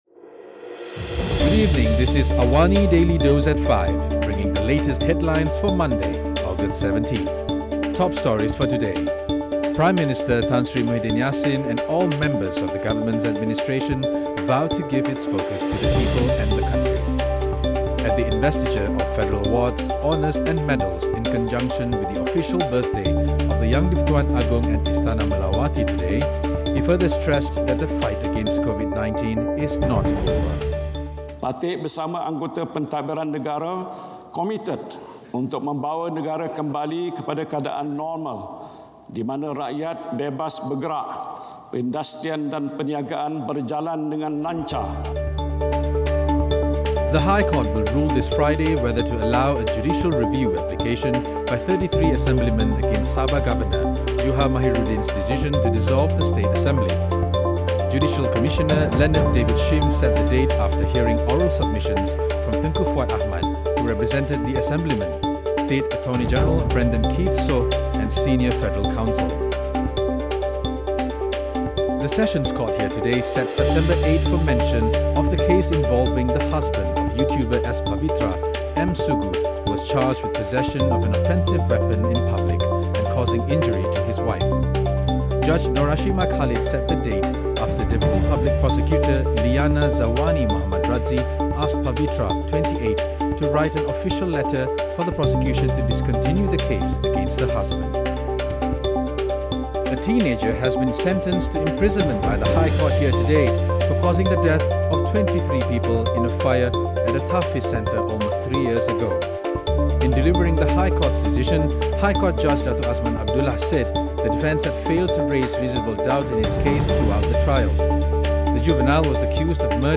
Listen to the top five stories of the day, reporting from Astro AWANI newsroom — all in 3 minutes.